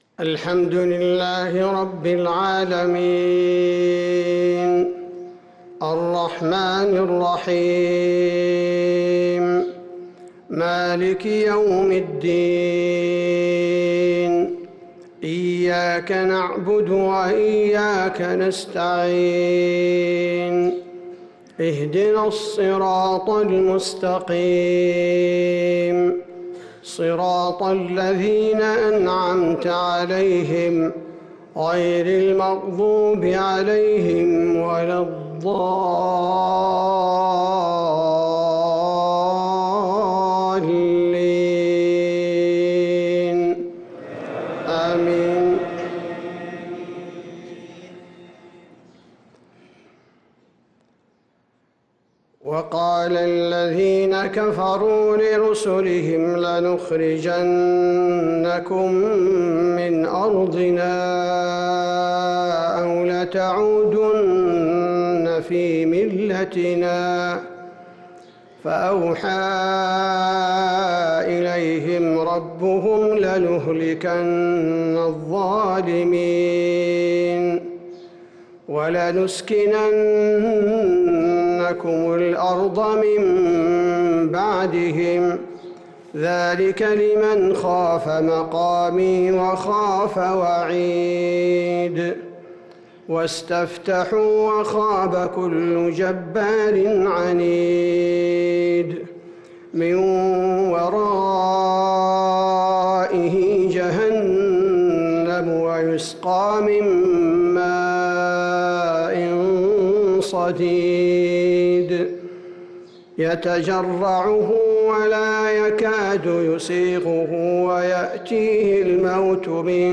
فجر الأحد 2-9-1446هـ من سورة إبراهيم 13-30 | Fajr prayer from Surat Ibrahim 2-3-2025 > 1446 🕌 > الفروض - تلاوات الحرمين